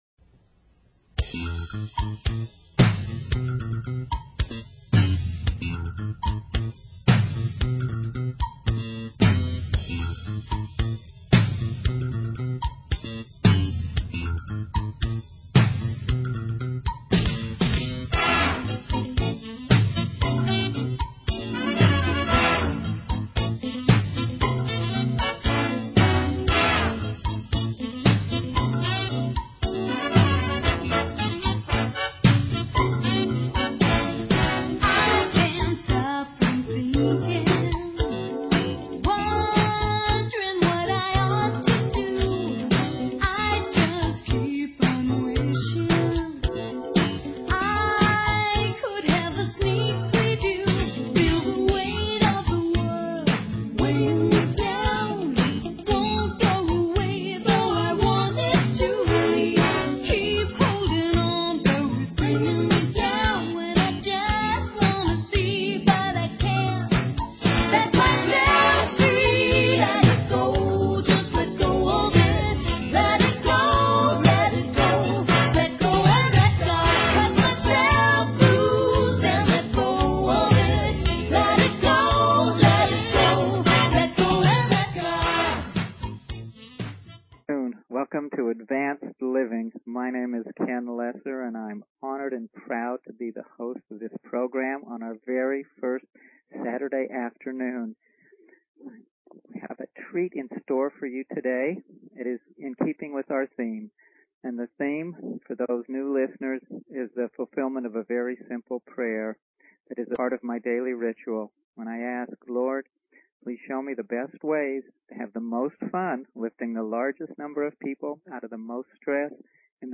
Talk Show Episode, Audio Podcast, Advanced_Living and Courtesy of BBS Radio on , show guests , about , categorized as